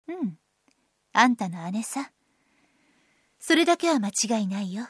サンプルボイスは各キャラクターの下にあります